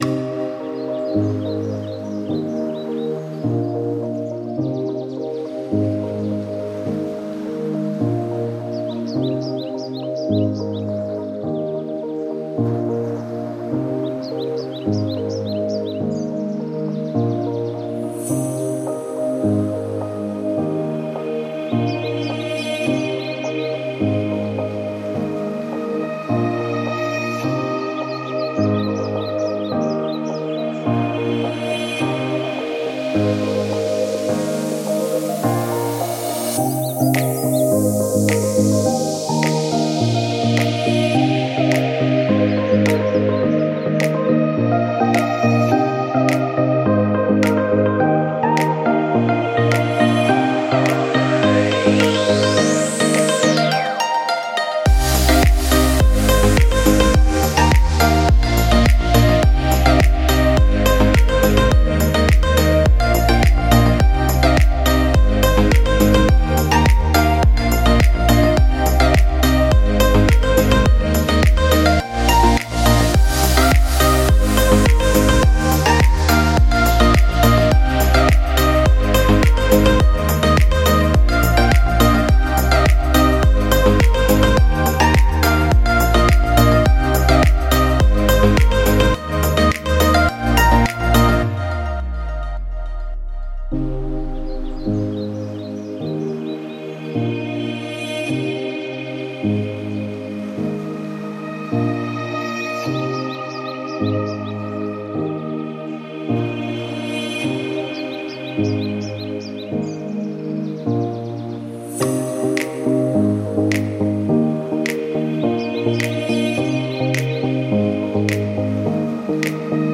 To listen to more royalty-free music from Instagram.